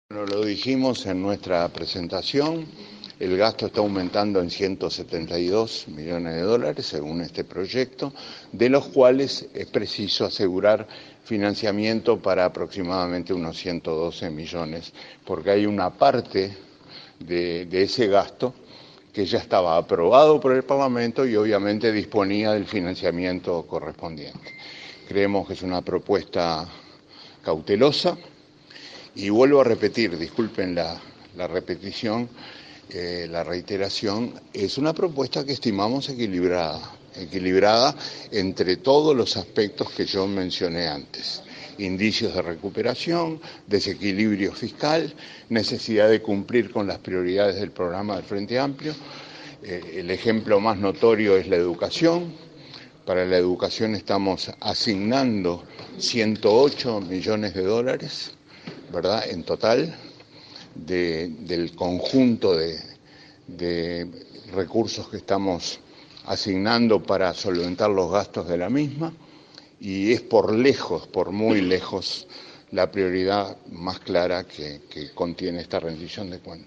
“Es una propuesta equilibrada entre los indicios de recuperación, el desequilibrio fiscal y el programa de gobierno”, afirmó el ministro de Economía, Danilo Astori, al entregar el proyecto de Rendición de Cuentas al Parlamento. Enfatizó que “el ejemplo más notorio es la educación a la que se le asignan 108 millones de dólares y es por muy lejos la prioridad más clara”.